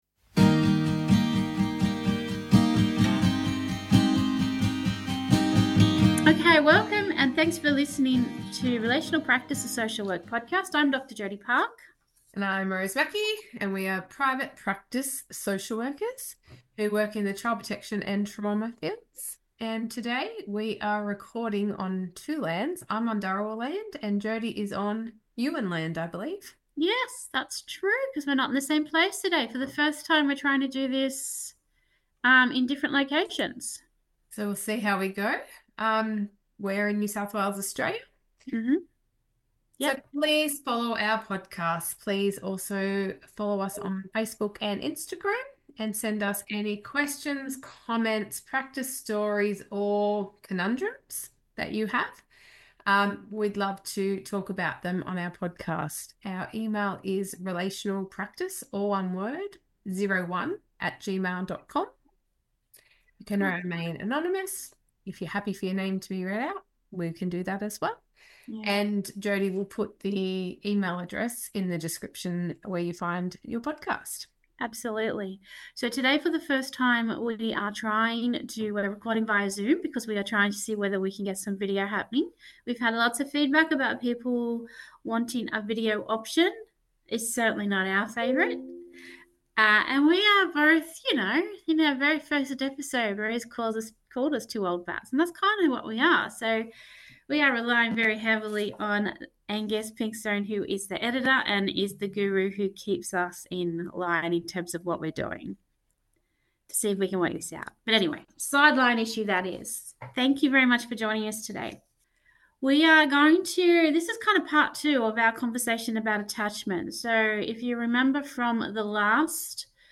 Join us for a podcast that feels like a conversation with friends.